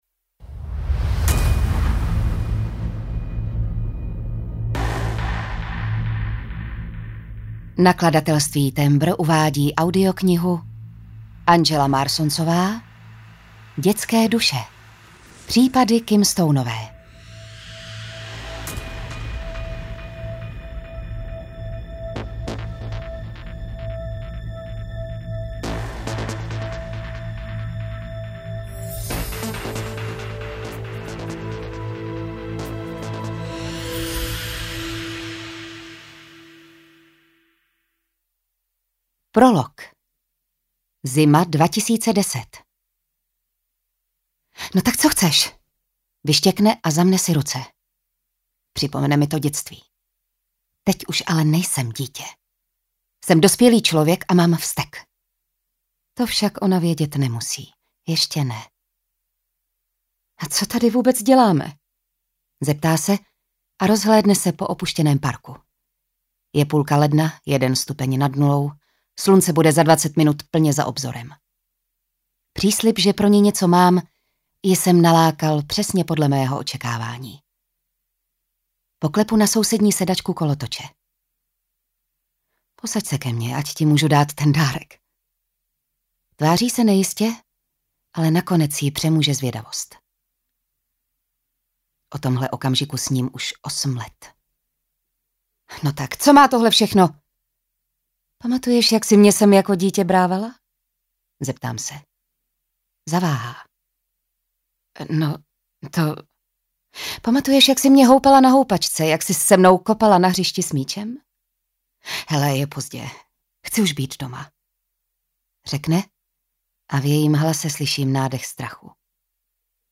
Ukázka z knihy
detske-duse-audiokniha